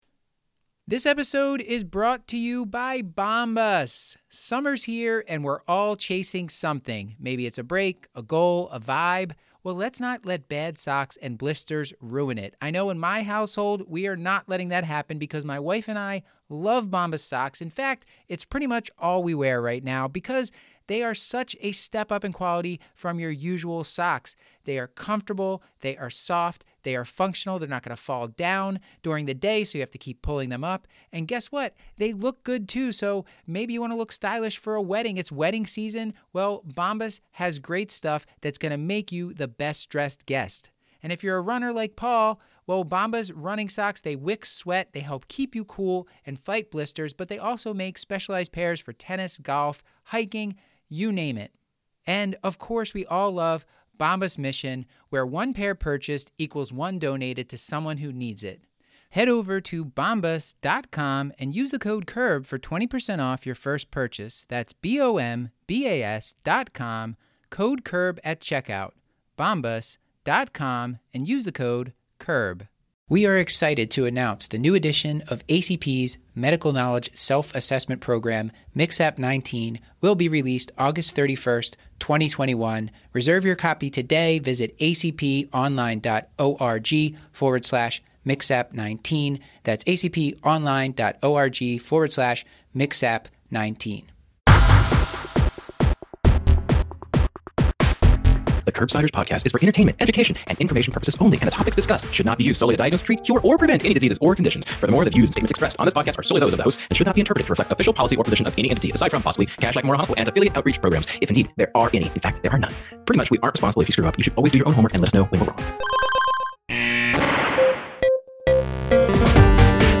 Intro, disclaimer, guest bio, Awful(ly good?) Pun